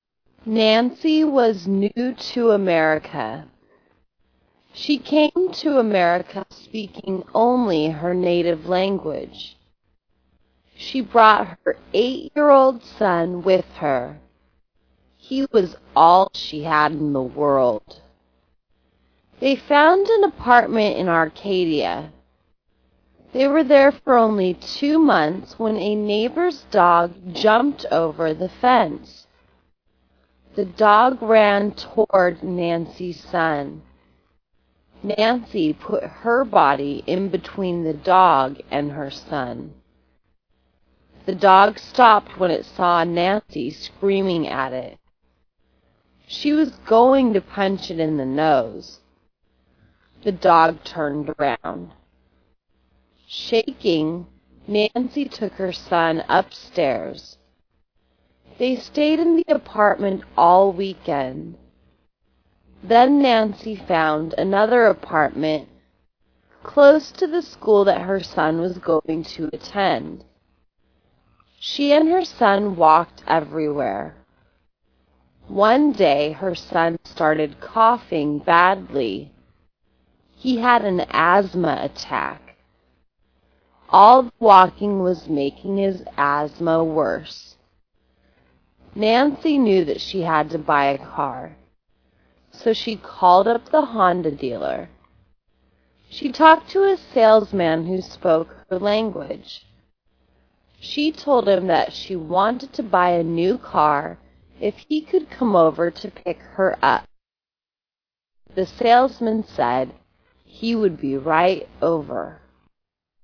Slow  Stop audio